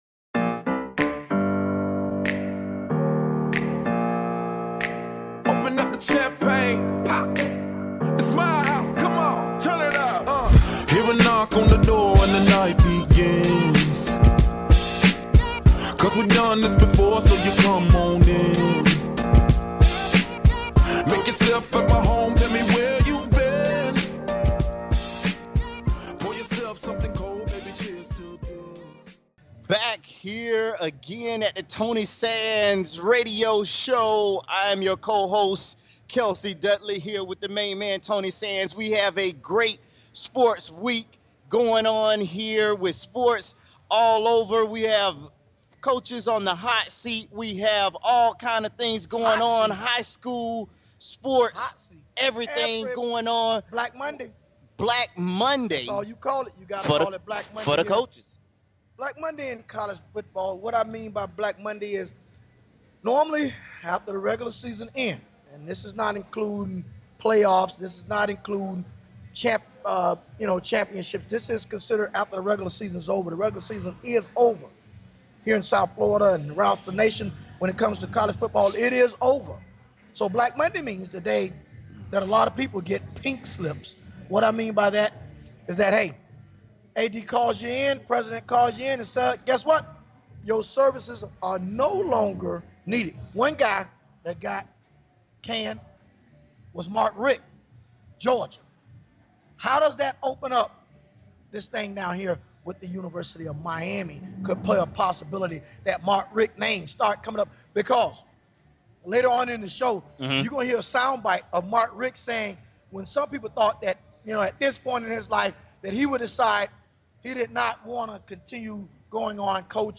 Talk Show Episode
at Tilt to Kilt Bar in South Florida - LIVE